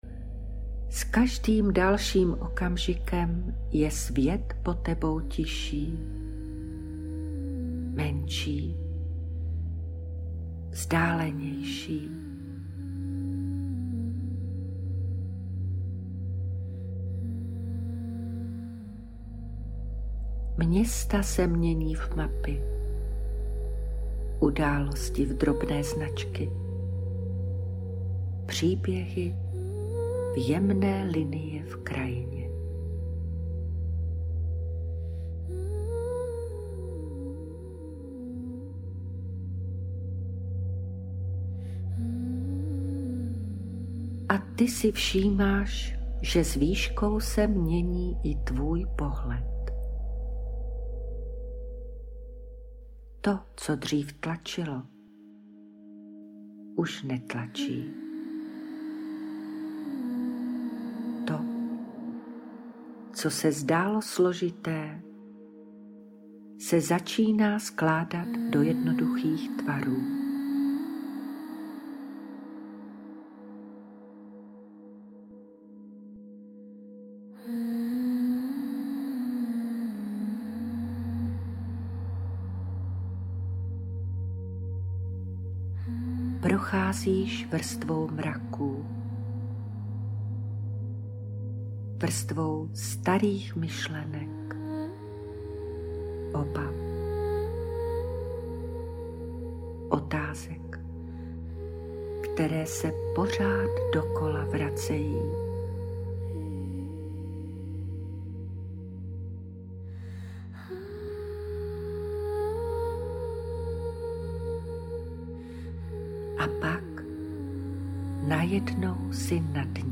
Ukázka z meditace Nad hlukem mysliCelková délka: 00:34:02
obsahuje čtyři vedené meditace, které na sebe jemně navazují, ale můžeš je používat i samostatně podle aktuální potřeby.